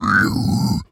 Minecraft Version Minecraft Version latest Latest Release | Latest Snapshot latest / assets / minecraft / sounds / mob / piglin_brute / angry4.ogg Compare With Compare With Latest Release | Latest Snapshot
angry4.ogg